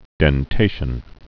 (dĕn-tāshən)